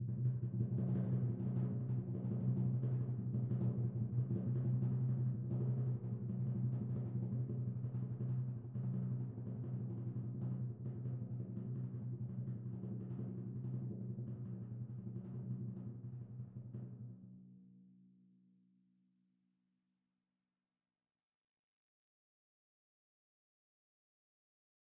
timpani2-roll-v3-rr1-sum.mp3